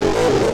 tekTTE63010acid-A.wav